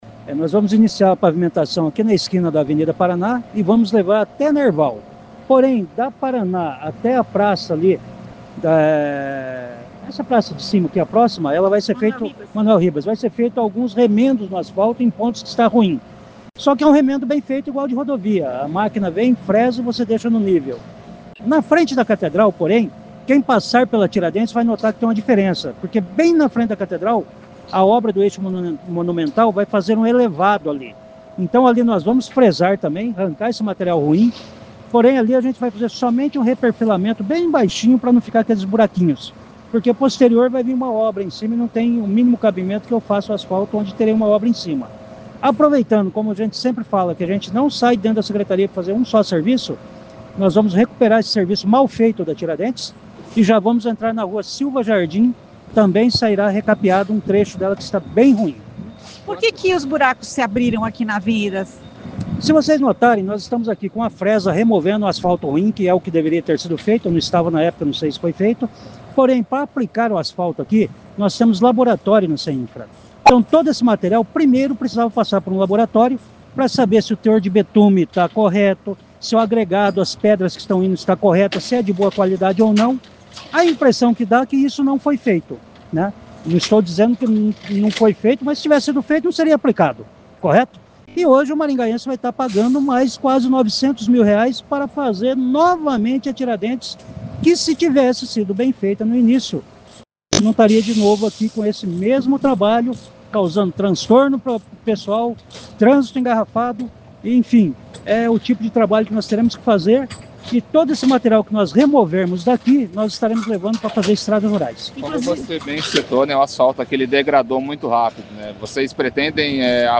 Ouça o que diz o secretário Vagner Mussio: